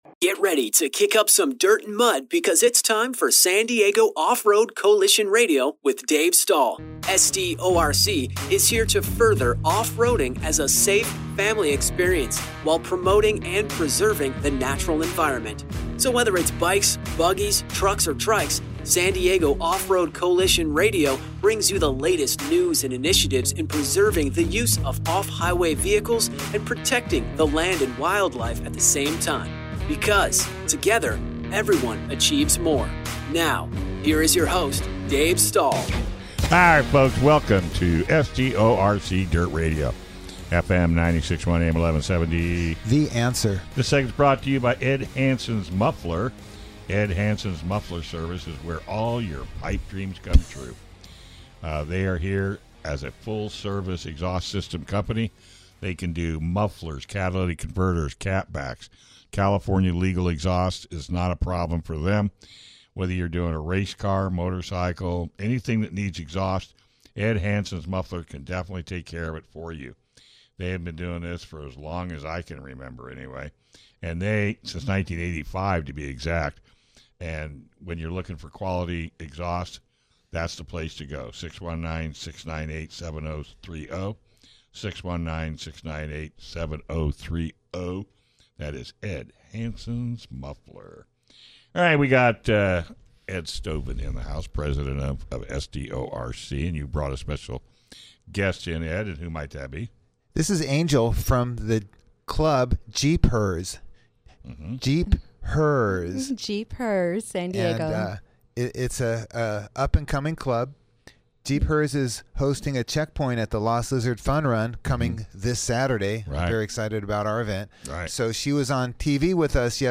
SDORC has its own radio show.